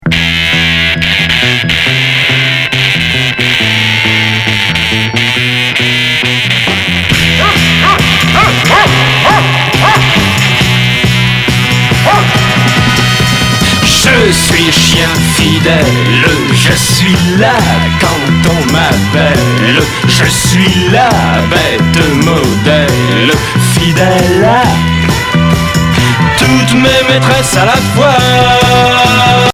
Chanteur 60's Quatrième 45t retour à l'accueil